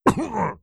Vampire_Hurt3.wav